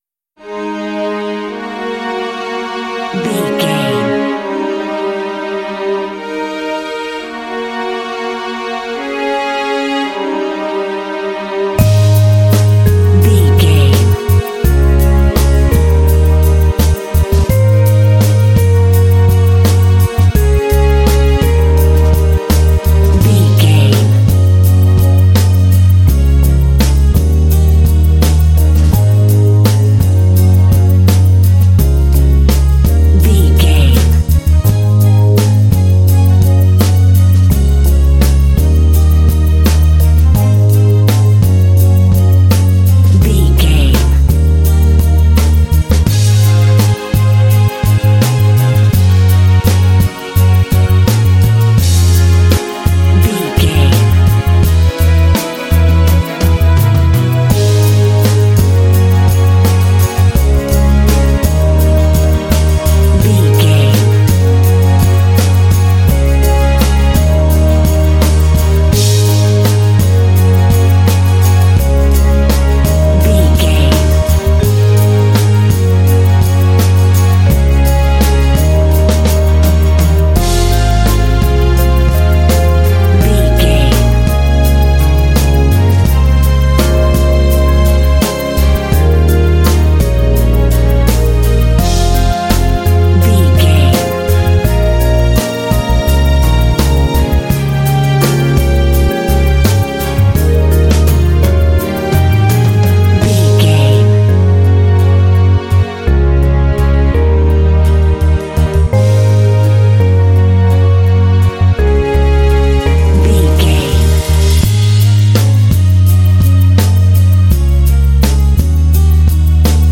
Relaxed, easy track, ideal for adventure games.
Mixolydian
A♭
relaxed
serene
mellow
soft
strings
drums
bass guitar
acoustic guitar
synthesiser
contemporary underscore